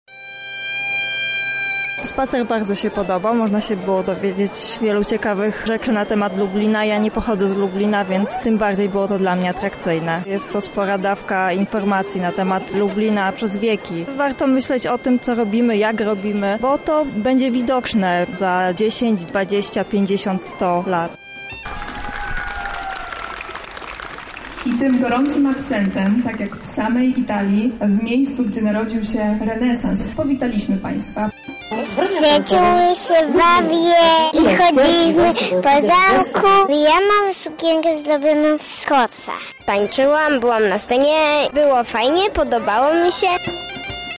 W Lublinie zakończył się II Międzynarodowy Festiwal Renesansu